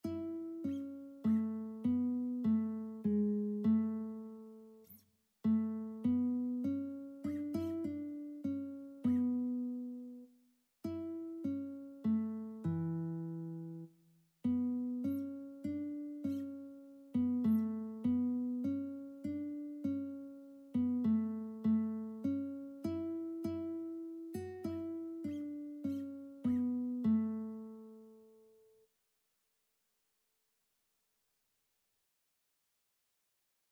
Christian Christian Lead Sheets Sheet Music Come, Thou Almighty King
A major (Sounding Pitch) (View more A major Music for Lead Sheets )
3/4 (View more 3/4 Music)
Classical (View more Classical Lead Sheets Music)